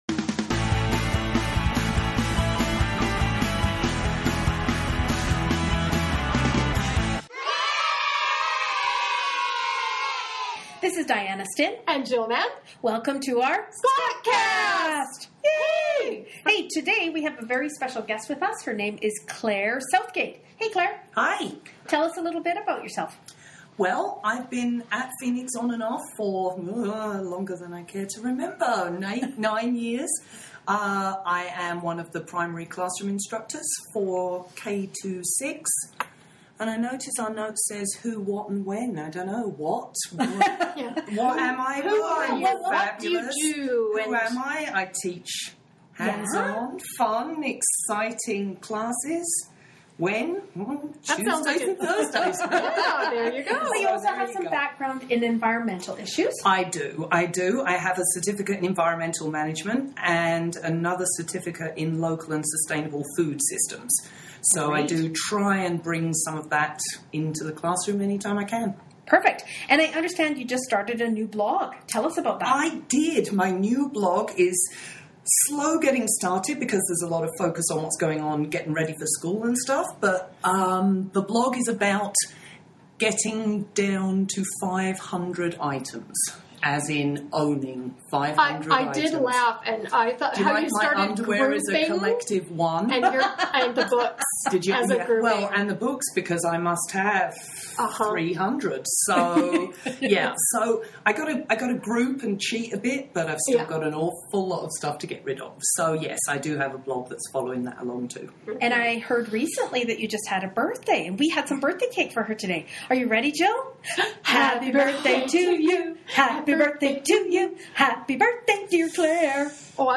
WARNING: Lots of laughter, some cool tips and a special guest –…